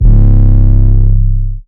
808 [ occulus ].wav